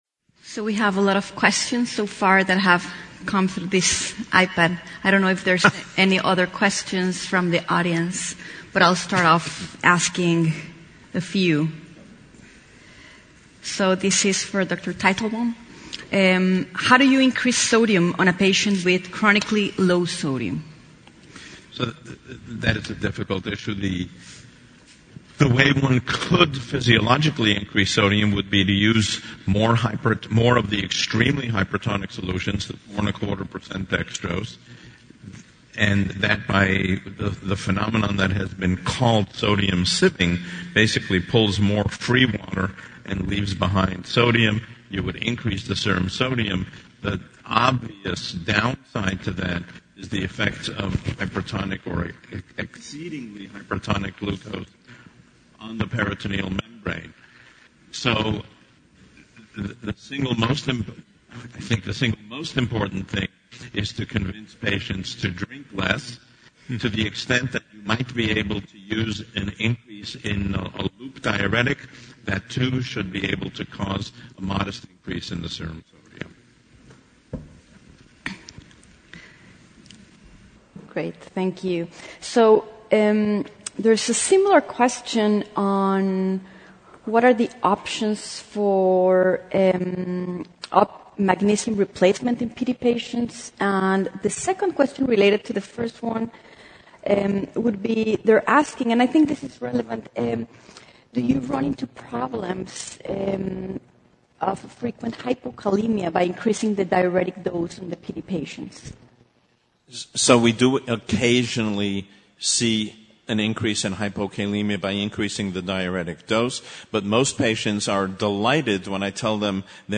MD Panel Discussions Drs.